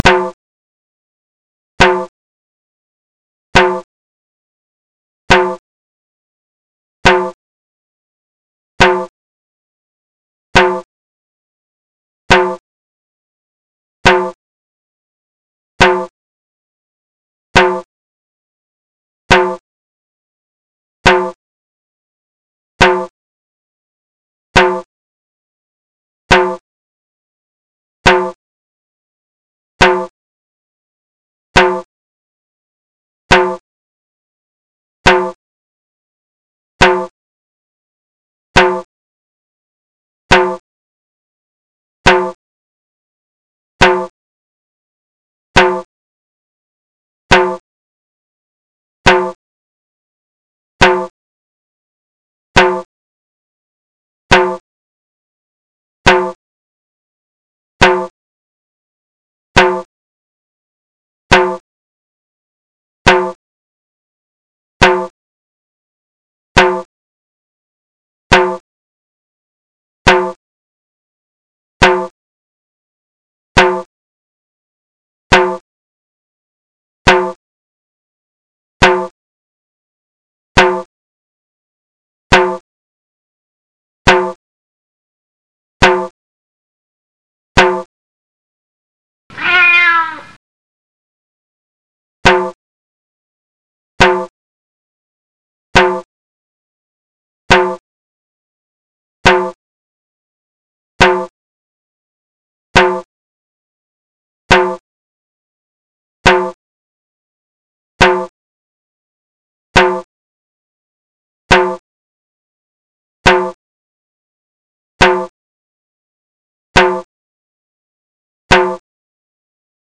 Wir haben ne neue Trommel…
Der Sound sollte nicht zu basslastig sein, damit wäre der kleine Lautsprecher überfordert.
Unseren Sound (Klingt nicht wirklich schön, aber funktioniert) kann man hier herunterladen, in den Zeiten 1,5s, 1,75s und 2s:
blechfass_1,75s_5min
blechfass_175s_5min.mp3